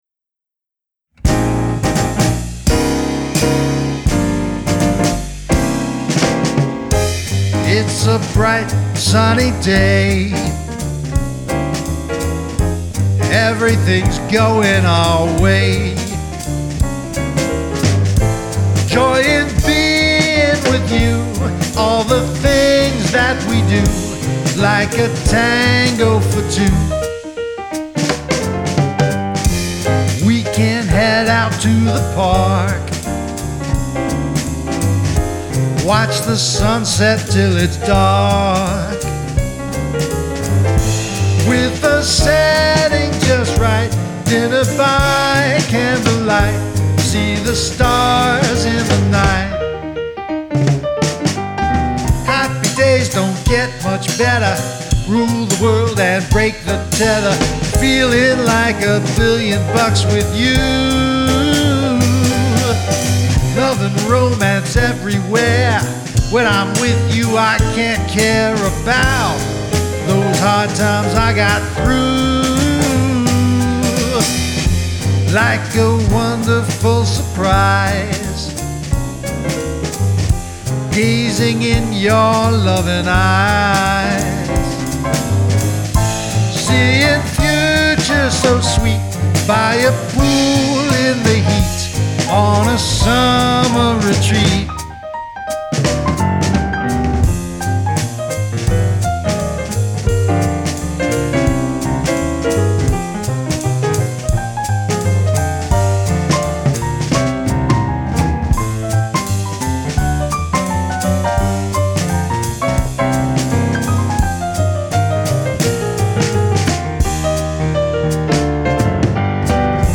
SINGER/MULTI-INSTRUMENTALIST & MUSIC PRODUCER
GRAMMY-recognized jazz musician
pianist, singer, trumpeter, and composer